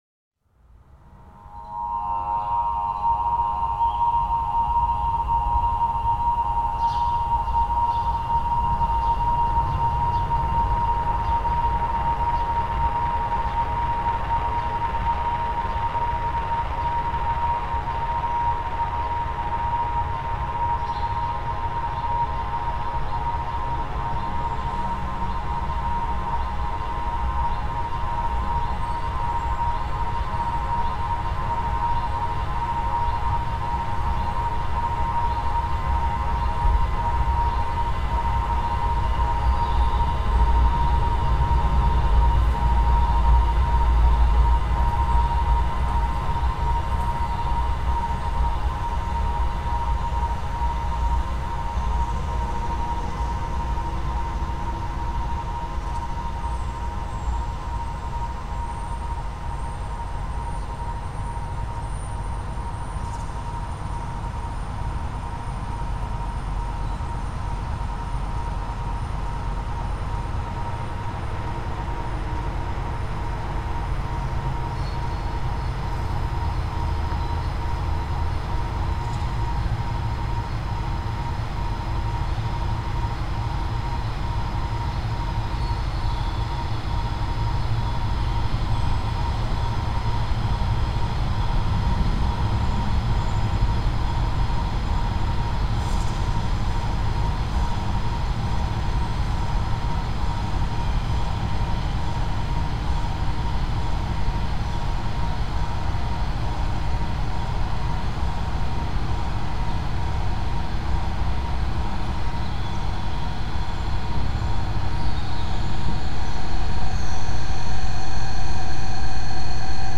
Voces Cohabitantes (2015) fixed media Program Notes Listen: Sorry.